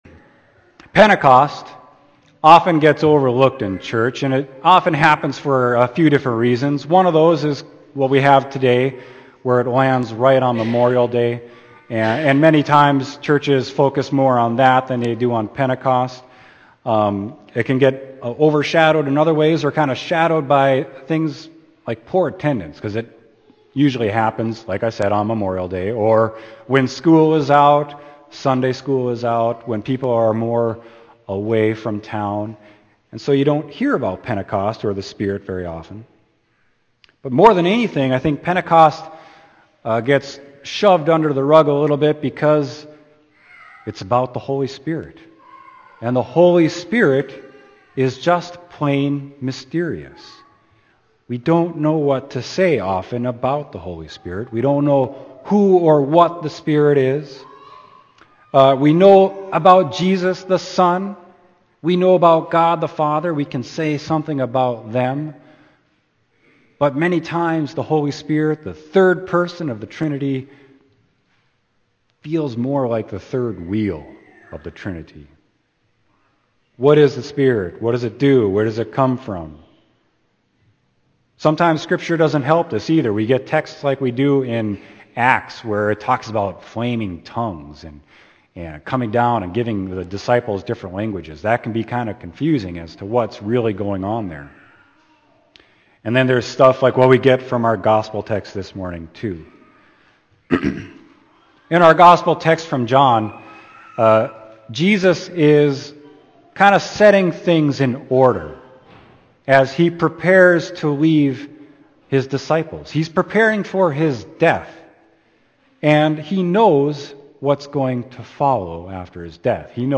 Sermon: John 15.26, 27; 16.4b-15